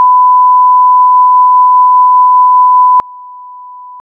Aquí se presentan los siguientes sonidos generados mediante goldwave que ilustran los fenómenos del umbral no lineal de audición, los efectos de enmascaramiento de una señal o la percepción logarítmica de la potencia de los sonidos.
Es decir, un tono de 1Khz que dura 3 segundos y otro tono mucho mas débil (un 93,75 % más débil) que dura tambien 3 segundos pero que va del segundo 1 al cuatro.